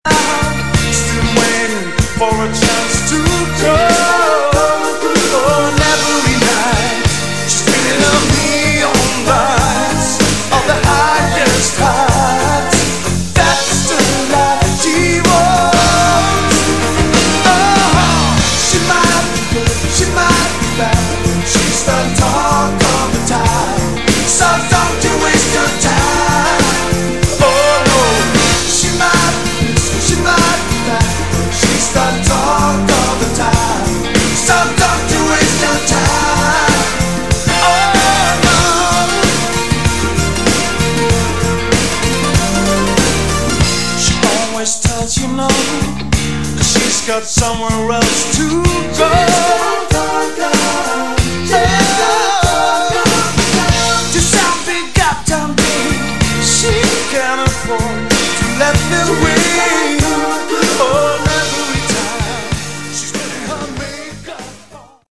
Category: Glam/Hard Rock
guitars, keyboards
vocals
drums